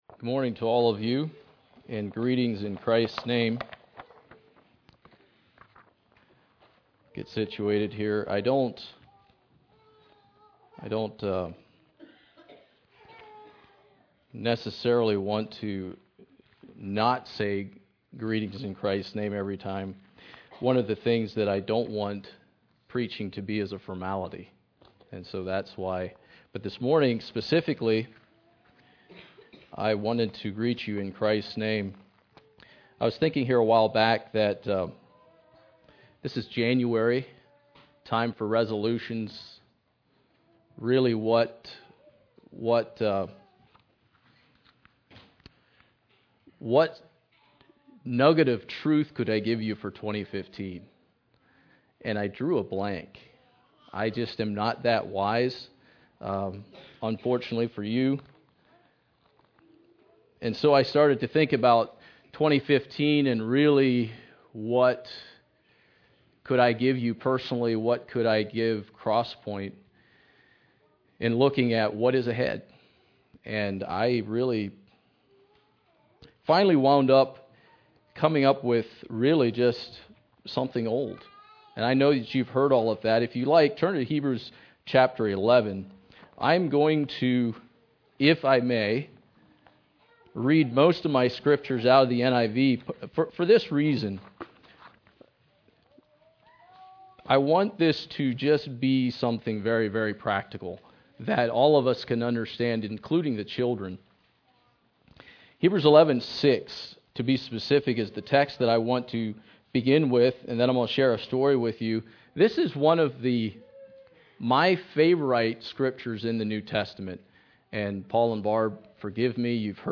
January 11, 2015 – Crosspointe Mennonite Church